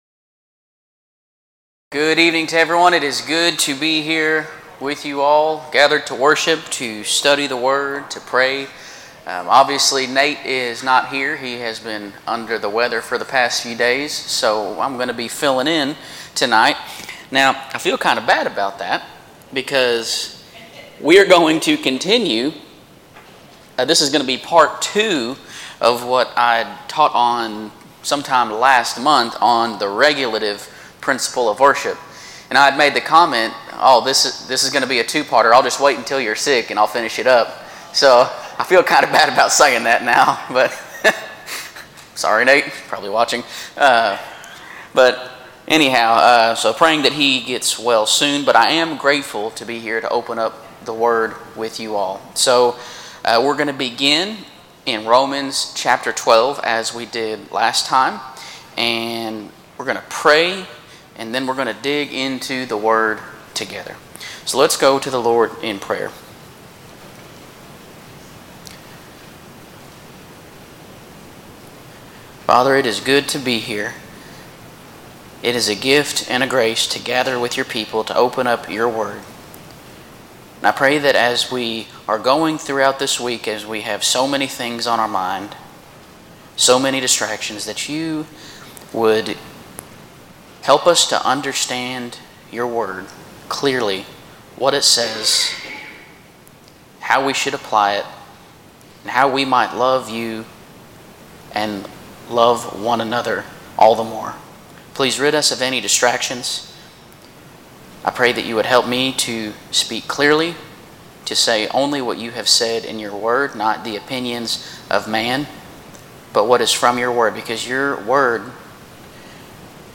Wednesday Evening Bible Studies - 6:30pm